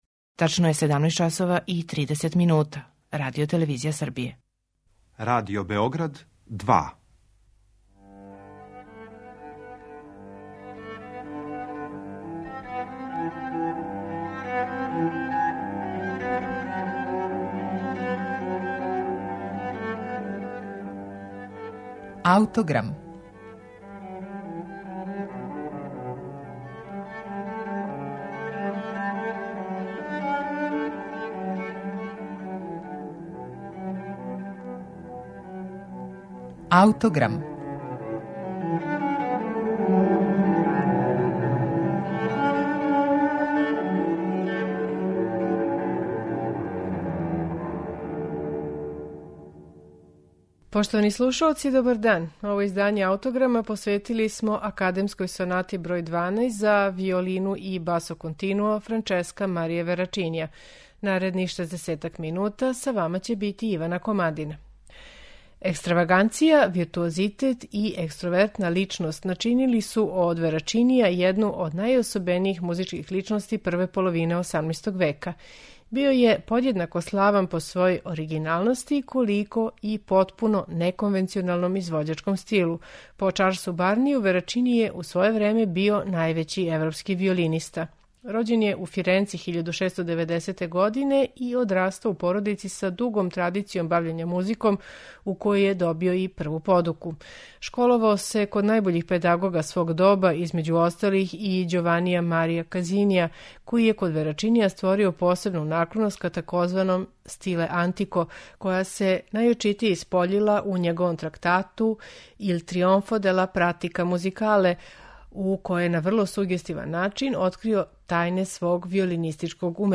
Верачинијева Академска соната број 12 у дe-молу за виолину и басо континуо, којој смо посветили данашњи Аутограм, открива га као једог од најблиставијих музичких умова 18. века. Настала је 1744. године, а Верачини је у њој демонстрирао изузетно контрапунктско умеће.